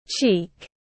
Cái má tiếng anh gọi là cheek, phiên âm tiếng anh đọc là /tʃiːk/.
Cheek /tʃiːk/